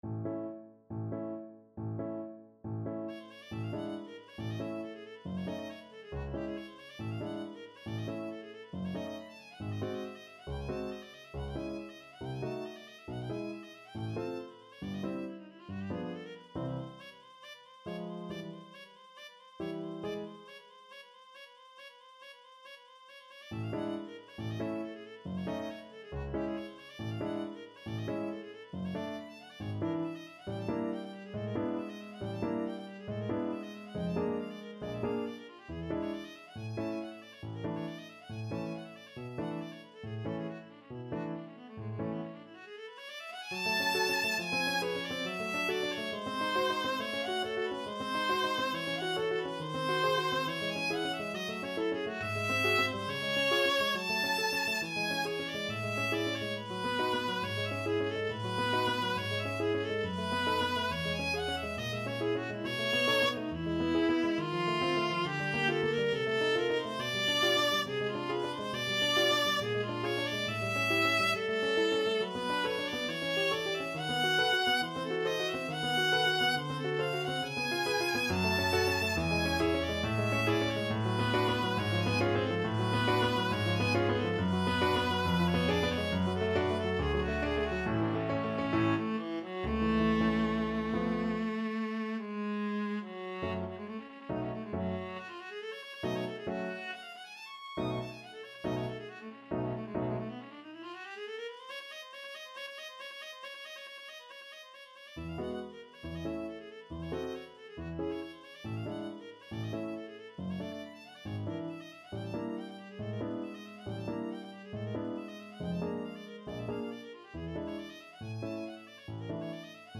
Viola
A major (Sounding Pitch) (View more A major Music for Viola )
2/4 (View more 2/4 Music)
Allegro vivo =138 (View more music marked Allegro)
Classical (View more Classical Viola Music)